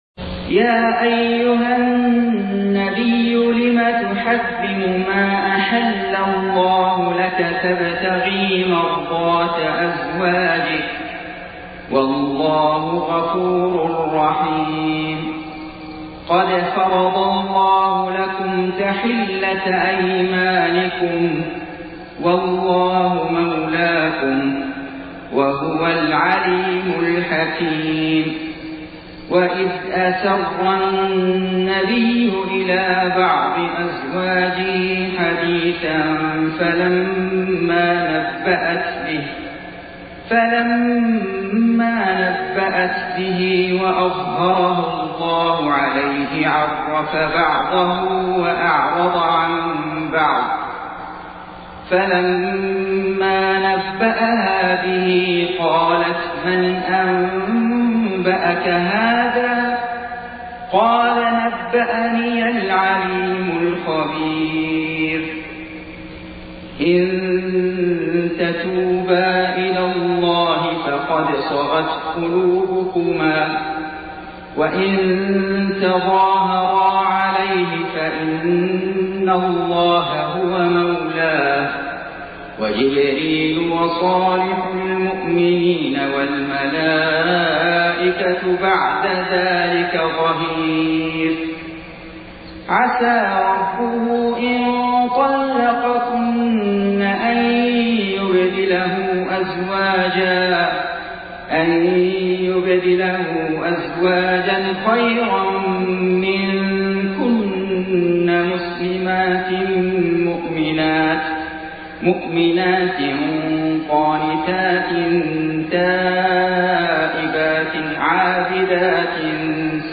سورة التحريم > السور المكتملة للشيخ فيصل غزاوي من الحرم المكي 🕋 > السور المكتملة 🕋 > المزيد - تلاوات الحرمين